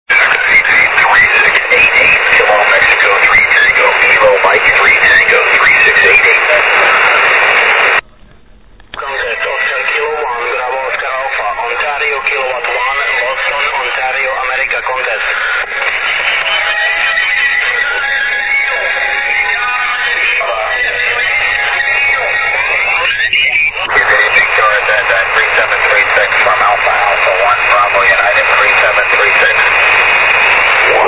Všechny nahrávky pocházejí z FT817.